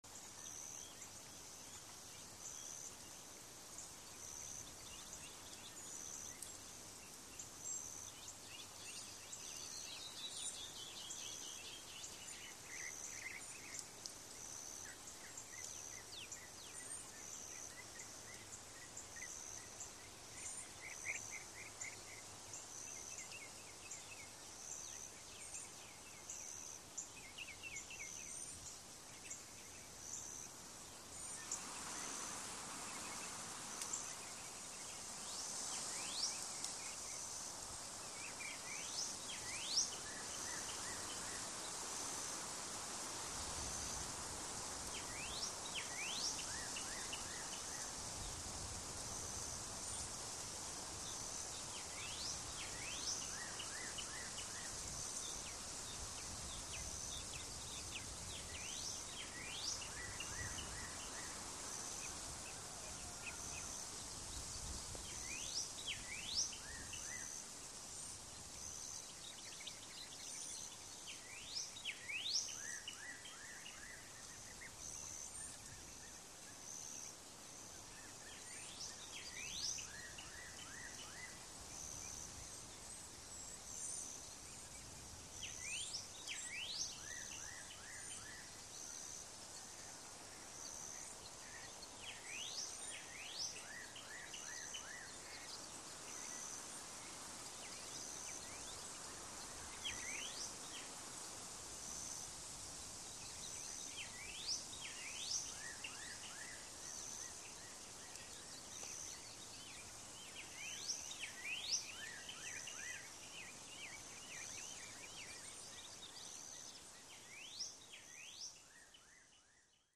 Wind, Gusts | Sneak On The Lot
Light Steady Breeze In Trees, With Birds And Insect Bed With Occasional Gusts.